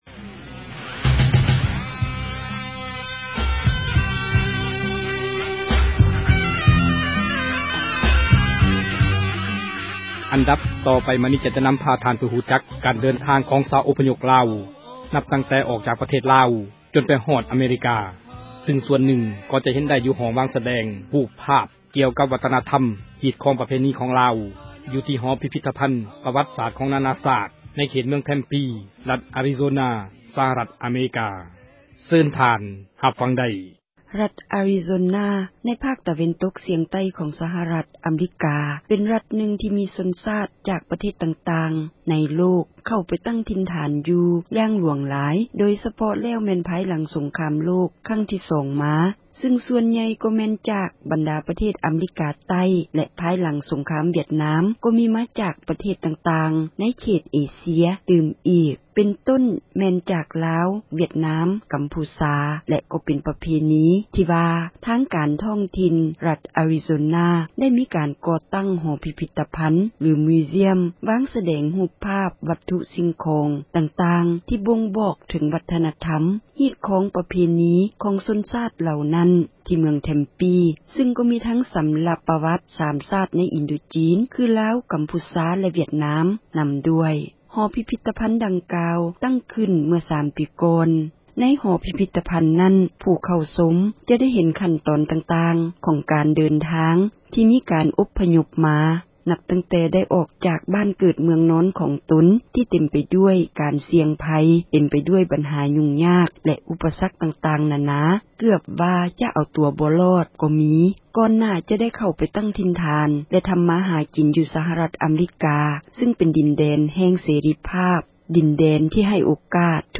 …..interview…..